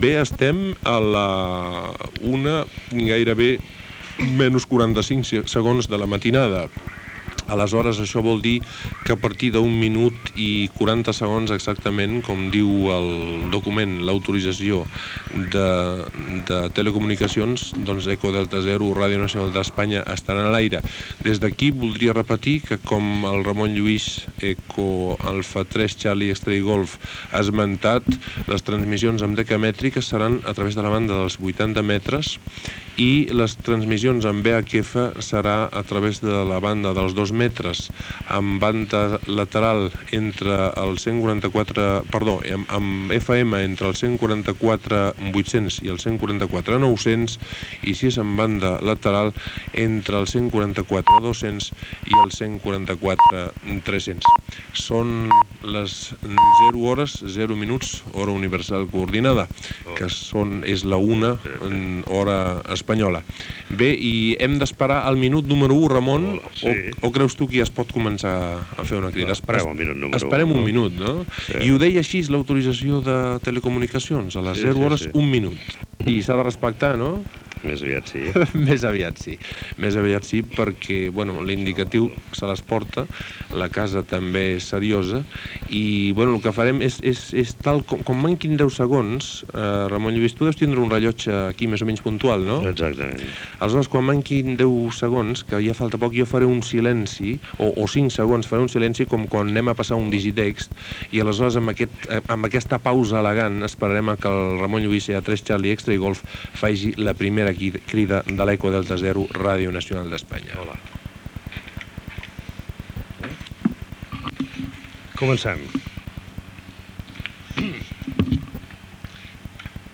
Divulgació
FM
Emtre el dia 8 i el dia 15 de desembre de 1986 funciona l'estació de radioaficionats ED0 RNE per commemorar els 10 anys de Ràdio 4. Aquesta estació va emetre des dels estudis del 6è pis del Passeig de Gràcia núm. 1 de Barcelona.